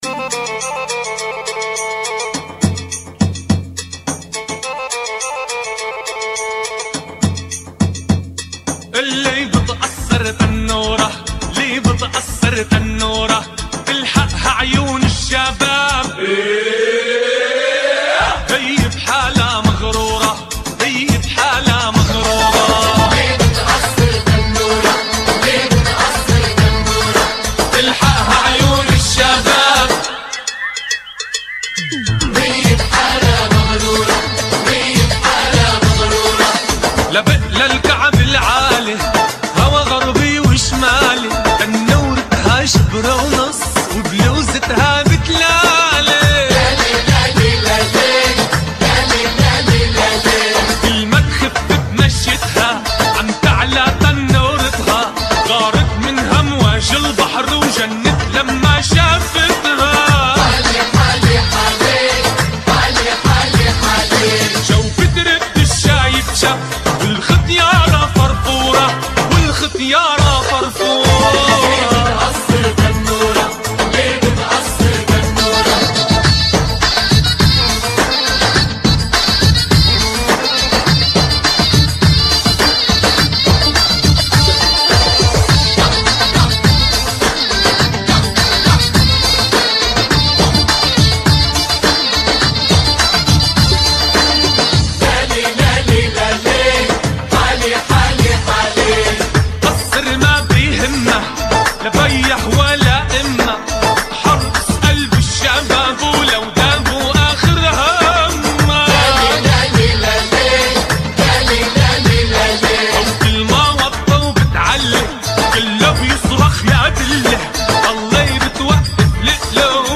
oriental